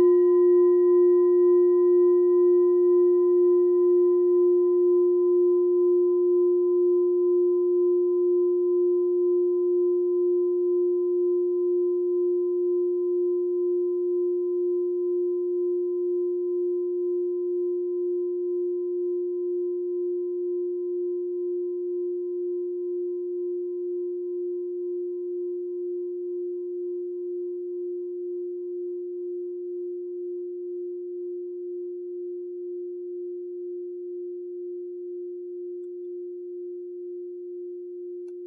Klangschale Nr.3 Bengalen, Planetentonschale: Mondkulmination
Filzklöppel oder Gummikernschlegel
Die Klangschale hat bei 187.4 Hz einen Teilton mit einer
Die Klangschale hat bei 191.44 Hz einen Teilton mit einer
klangschale-bengalen-3.wav